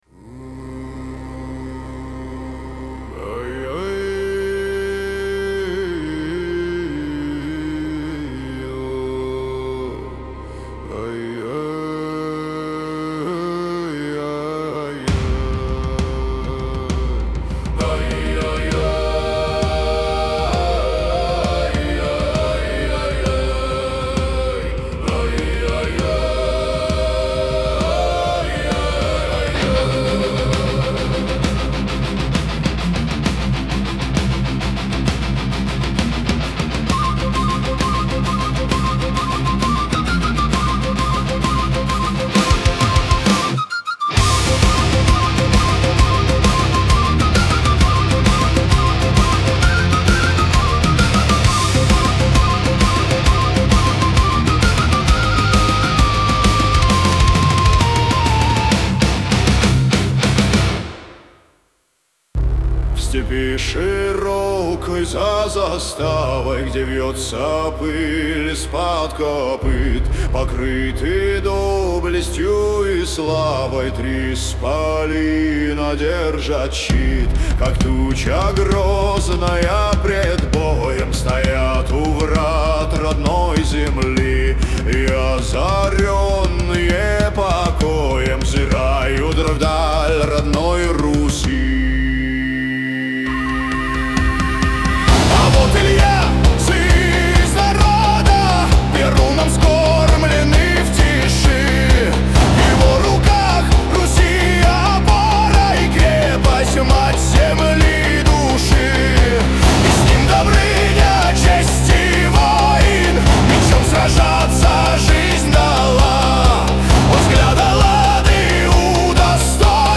Трек размещён в разделе Рэп и хип-хоп / Русские песни / Поп.